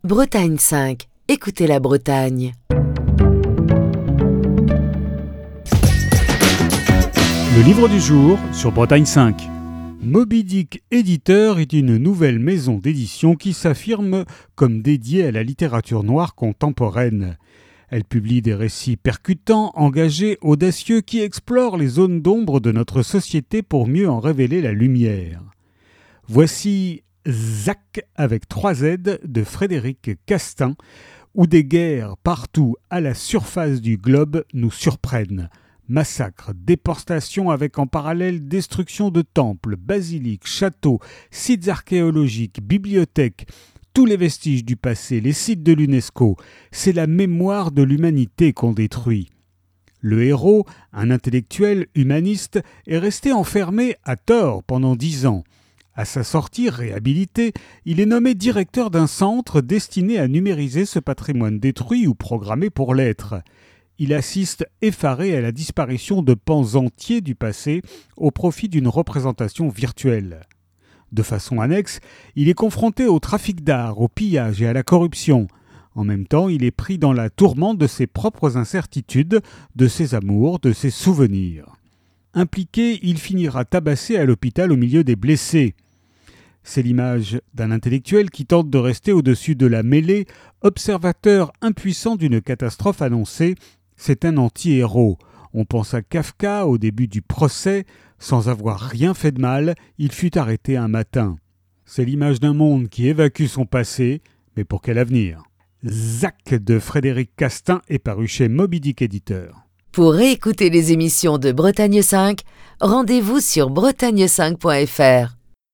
Chronique du 23 décembre 2025.